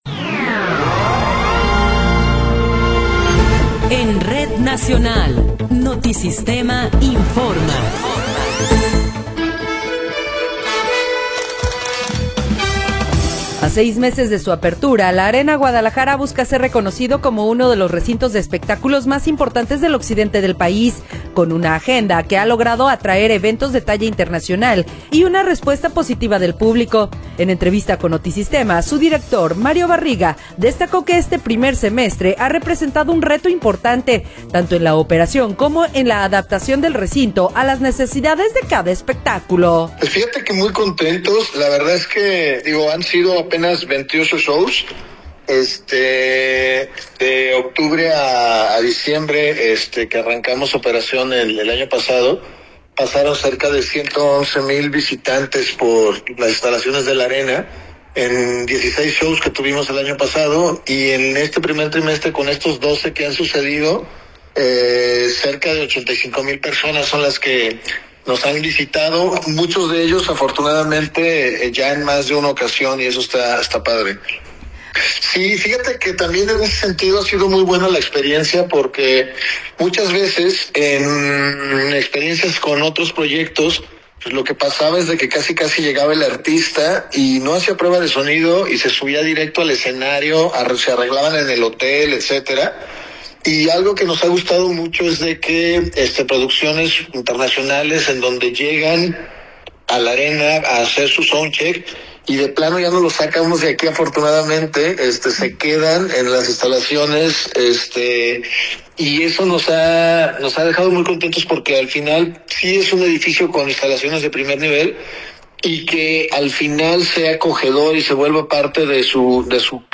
Noticiero 10 hrs. – 26 de Abril de 2026